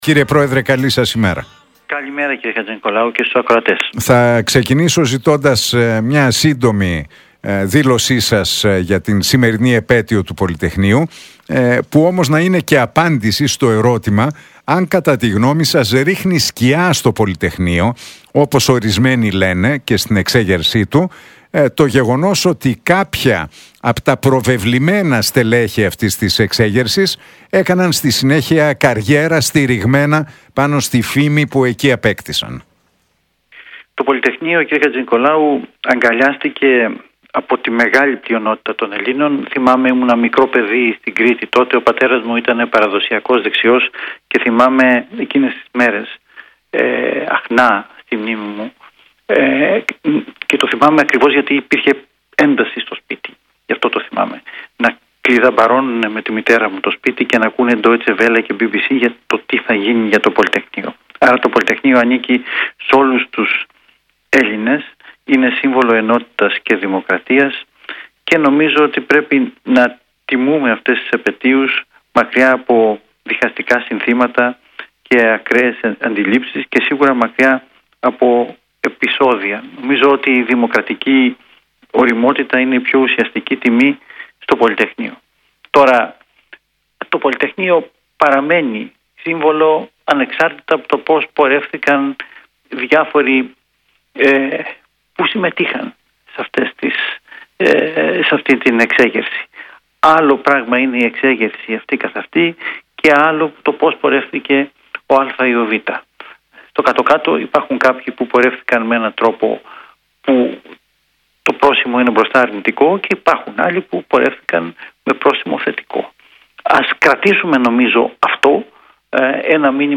Για την επέτειο του Πολυτεχνείου, τον ΟΠΕΚΕΠΕ, τα ΕΛΤΑ και τις εξελίξεις στα ενεργειακά μίλησε ο αντιπρόεδρος της κυβέρνησης Κωστής Χατζηδάκης, μιλώντας στην εκπομπή του Νίκου Χατζηνικολάου στον Realfm 97,8.